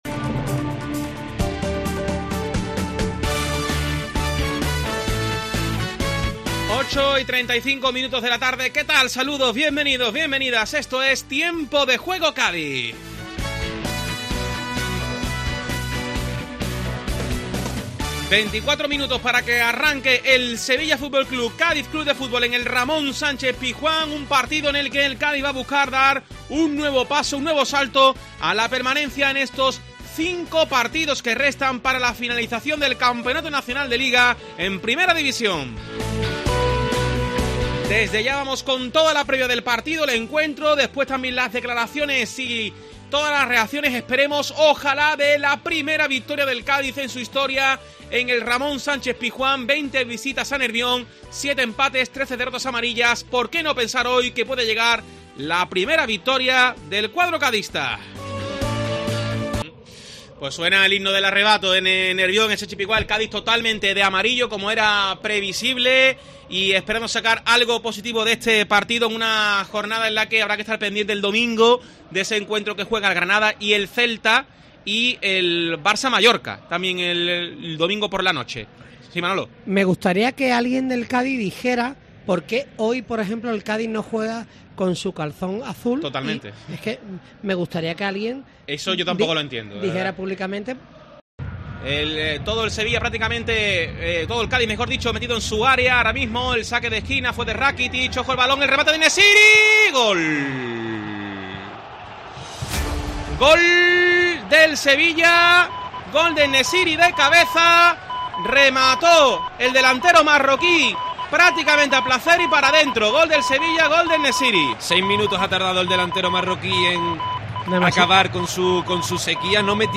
Escucha el resumen sonoro del partido con los goles y la recta final de un emocionante encuentro en el Ramón Sánchez Pizjuán